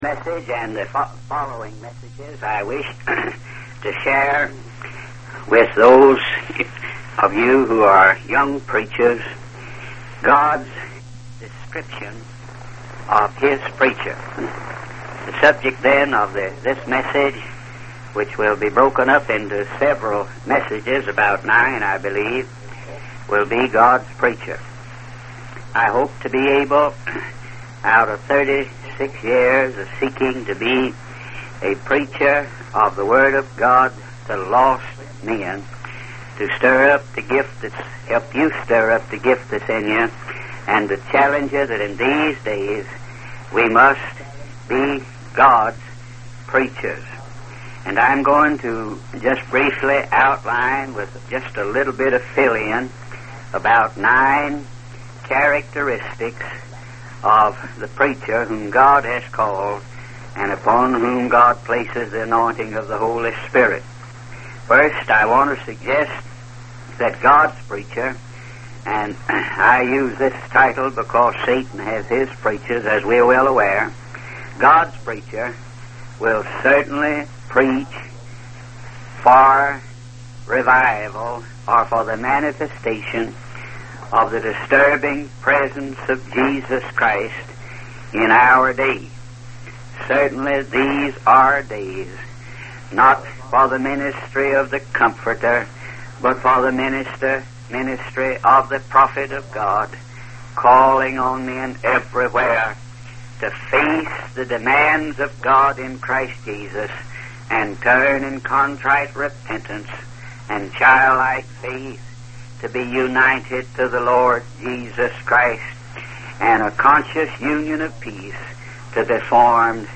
In this sermon, the preacher emphasizes the importance of preaching for a verdict, rather than just going through the motions. He calls on preachers to be like John the Baptist, urging people to surrender in repentance and faith to God's Son.